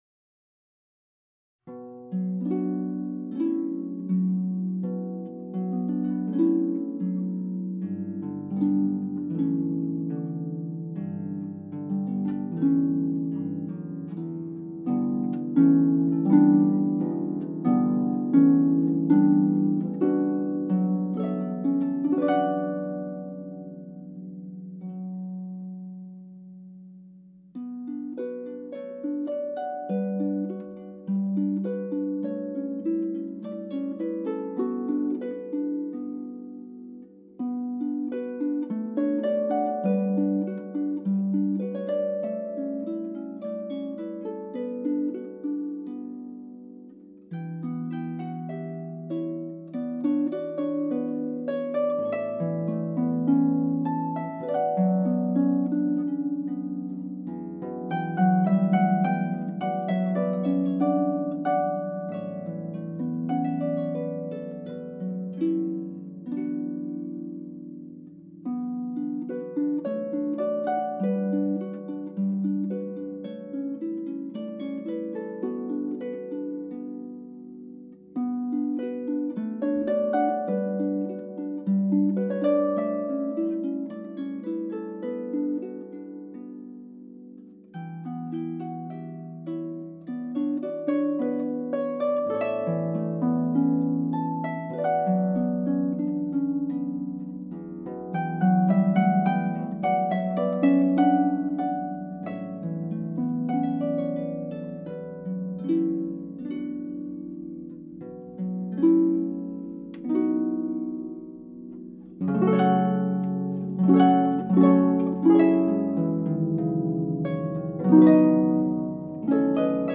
pedal harp solo